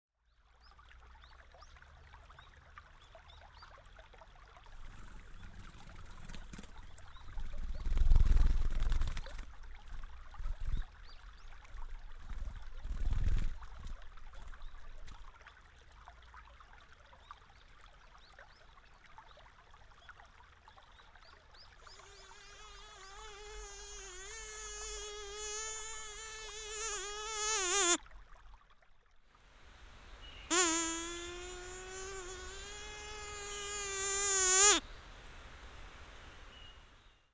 3D spatial surround sound "Flying insects"
3D Spatial Sounds